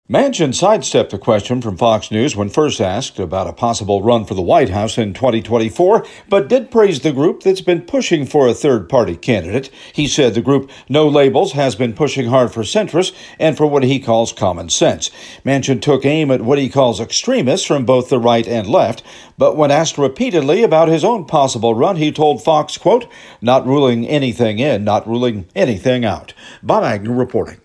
Reporting from Capitol Hill